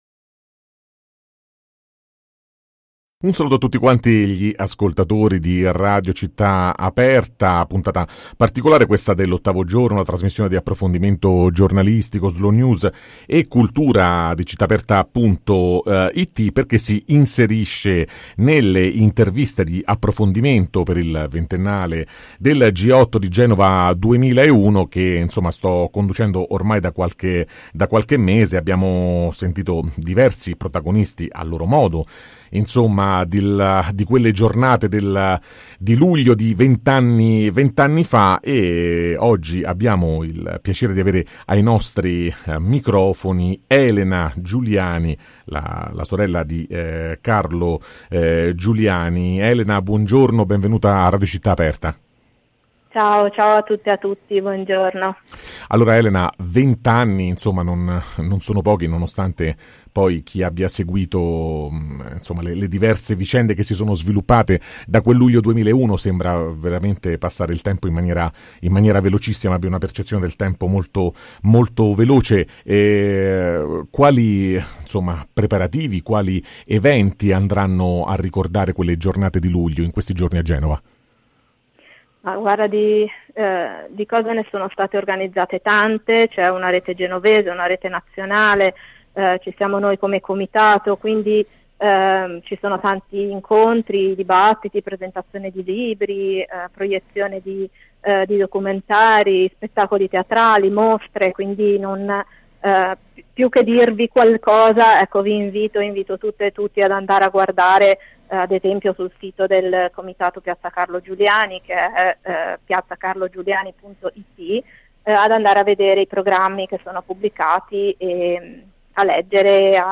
Podcast intervista